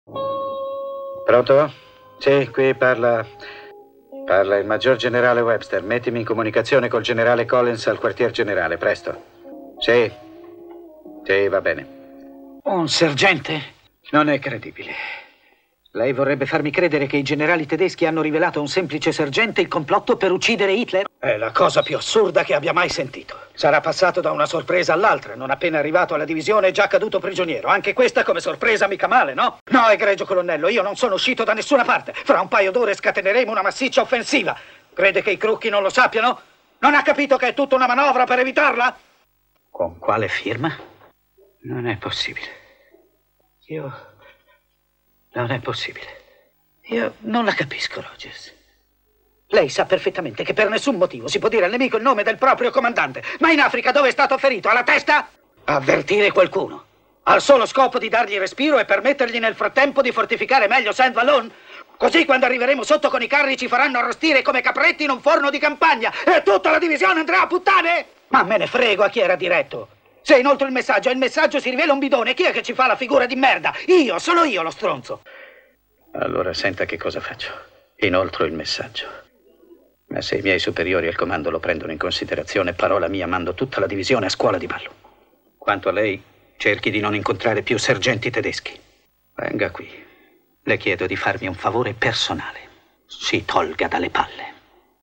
nel film "Specchio per le allodole", in cui doppia Rod Steiger.